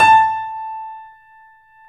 Index of /90_sSampleCDs/E-MU Producer Series Vol. 5 – 3-D Audio Collection/3D Pianos/BoesPlayHardVF04